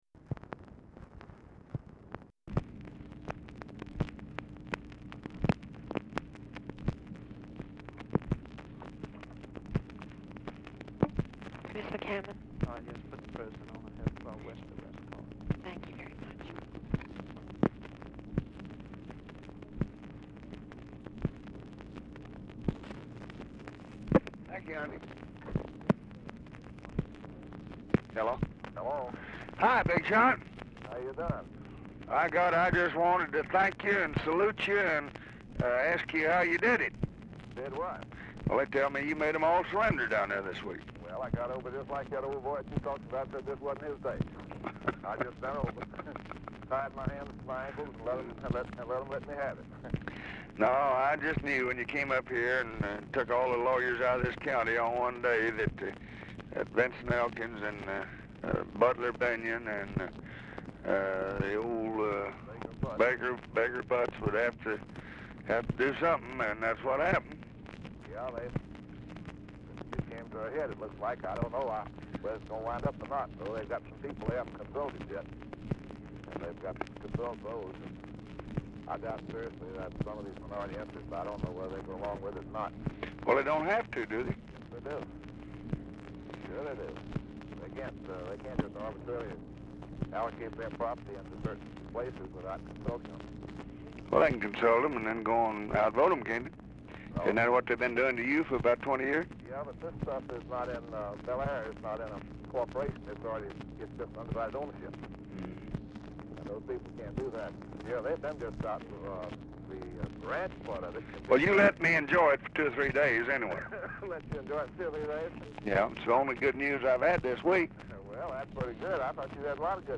Telephone conversation
RECORDING IS ACCIDENTALLY CUT OFF
Format Dictation belt